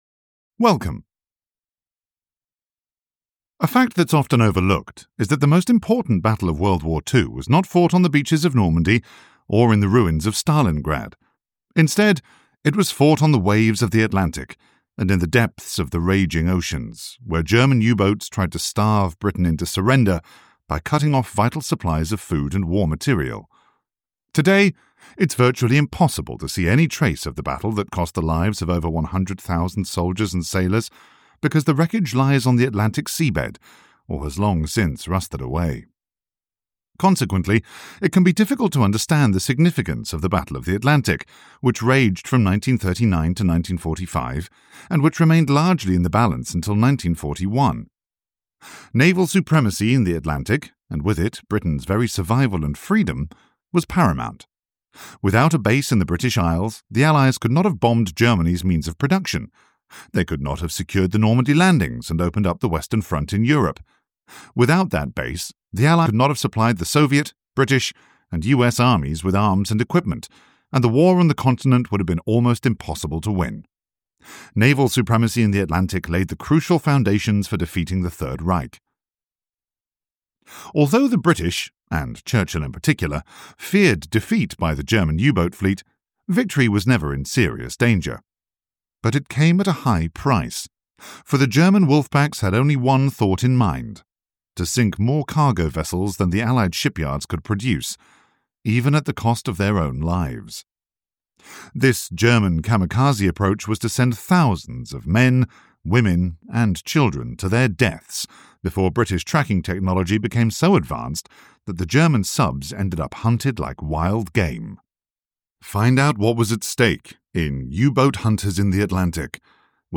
U–Boat Hunters in the Atlantic (EN) audiokniha
Ukázka z knihy